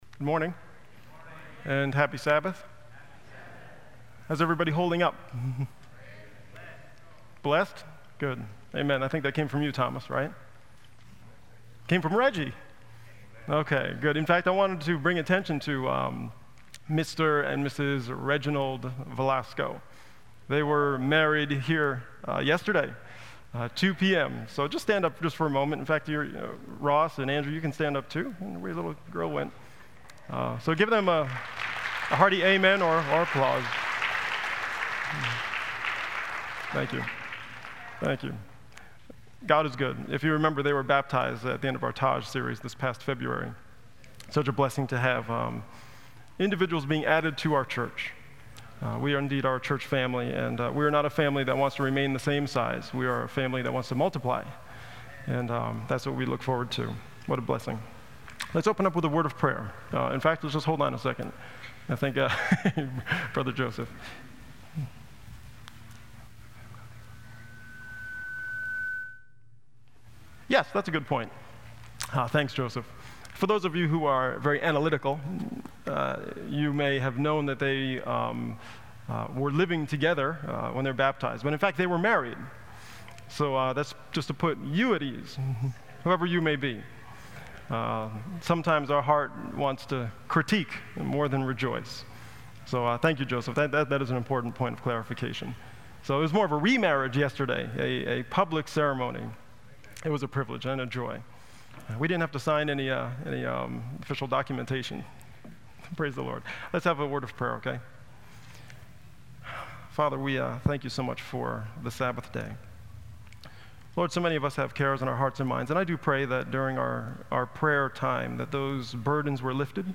on 2015-06-19 - Sabbath Sermons